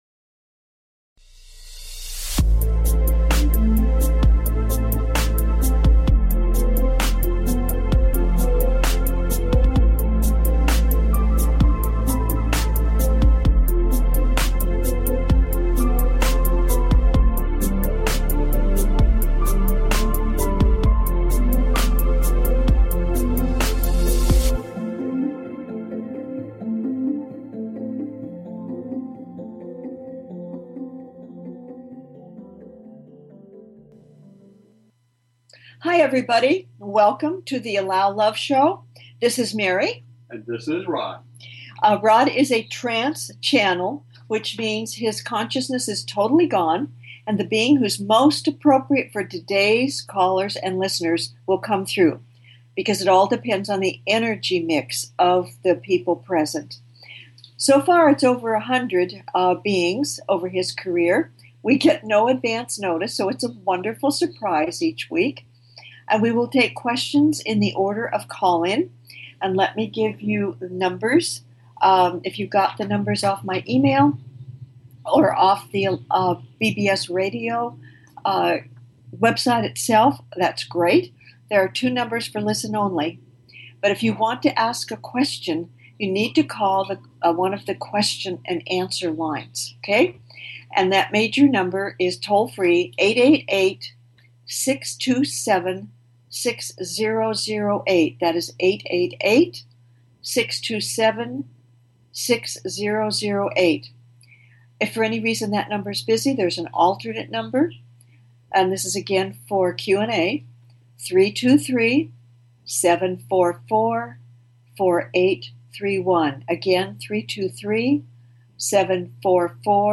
Talk Show Episode, Audio Podcast, The Allow Love Show and with Saint Germain on , show guests , about Saint Germain, categorized as Paranormal,Ghosts,Philosophy,Spiritual,Access Consciousness,Medium & Channeling